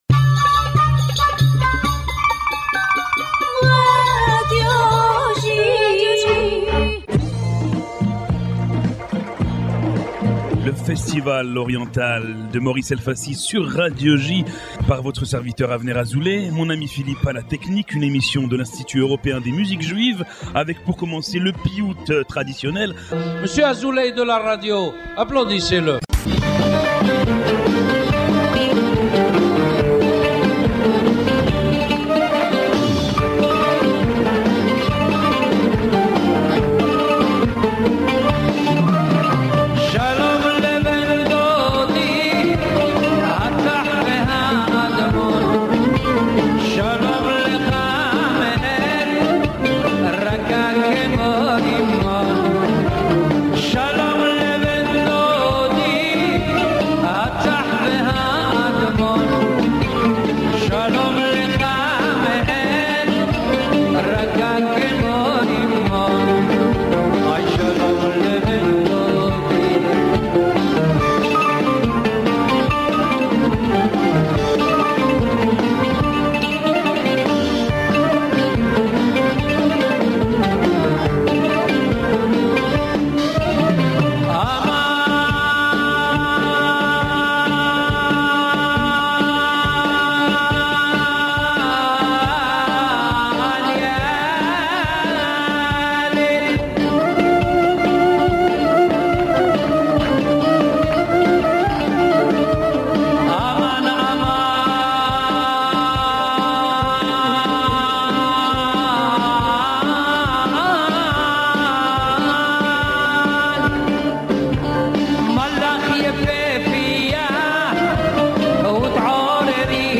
dédiée à la musique orientale
piyout traditionnel marocain
folklore marocain
classique algérois
classique égyptien
variété israélienne
folklore tunisien
classique constantinois